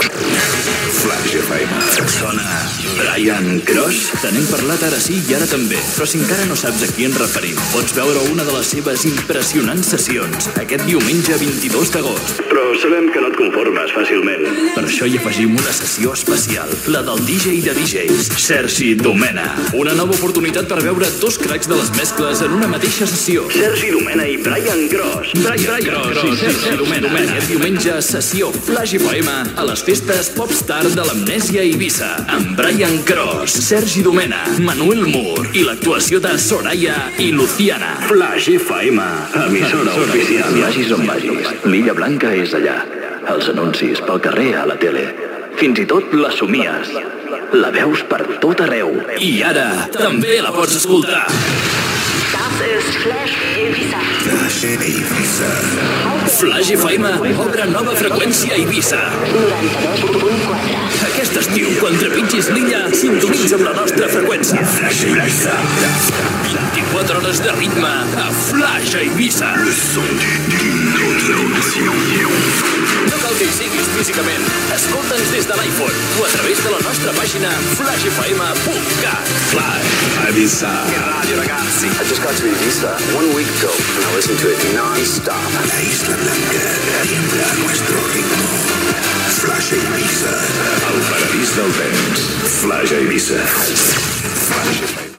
Indicatiu, sessió de DJ de Flaix FM a les festes Pop Star d'Eivissa, anunci de la nova freqüència Flaix Eivissa